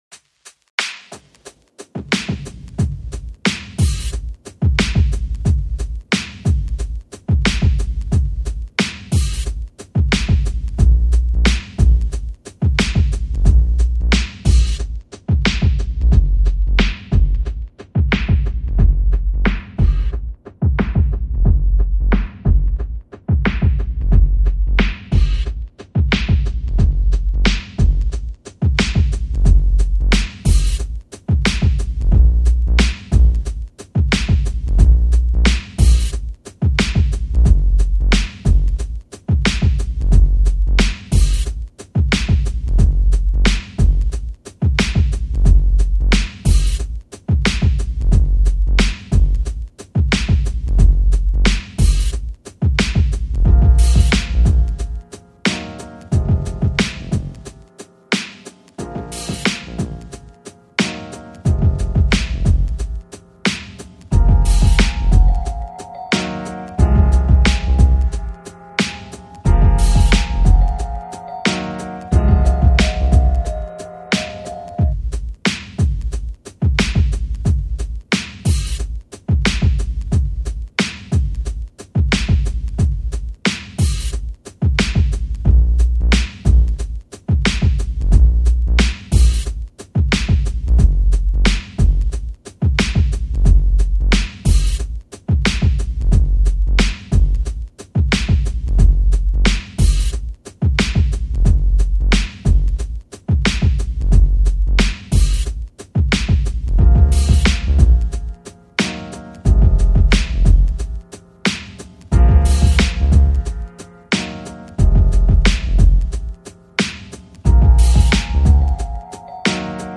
Electronic, Lounge, Chill Out, Chill Hop